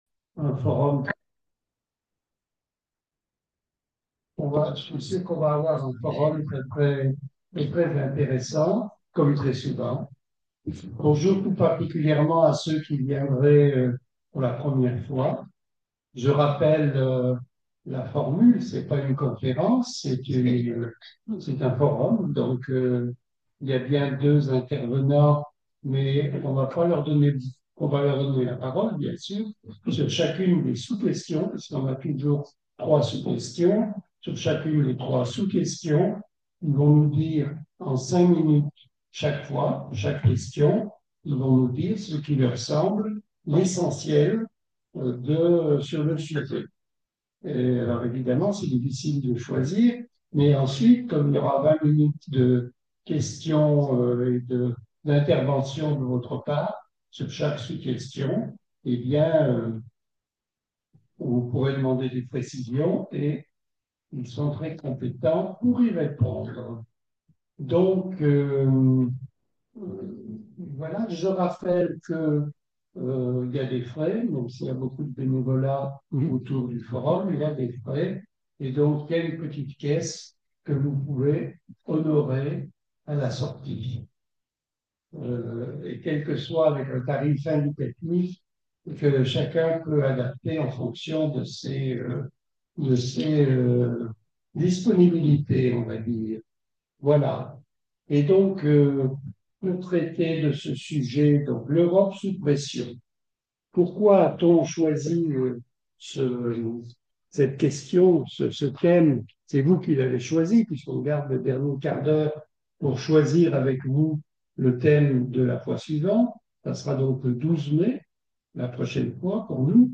Forum de l’événement – 24 mars 2025 – Centre théologique de Meylan L’Europe sous pression